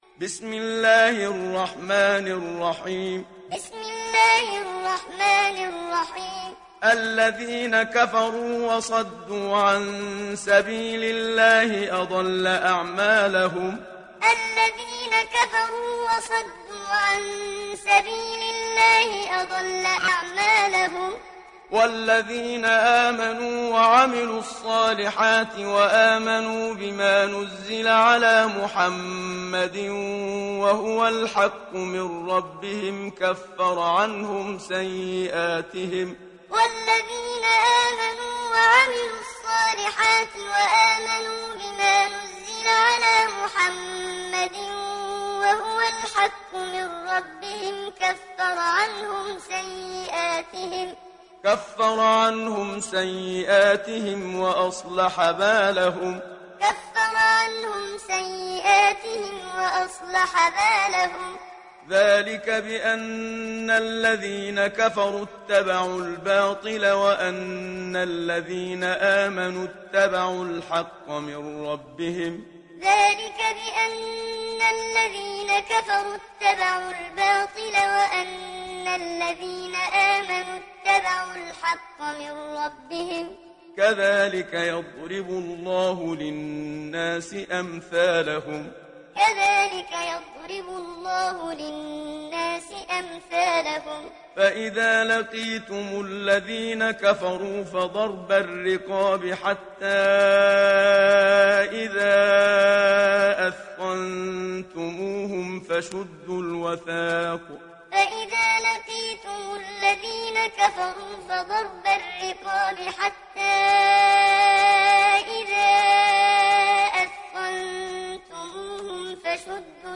সূরা মুহাম্মাদ ডাউনলোড mp3 Muhammad Siddiq Minshawi Muallim উপন্যাস Hafs থেকে Asim, ডাউনলোড করুন এবং কুরআন শুনুন mp3 সম্পূর্ণ সরাসরি লিঙ্ক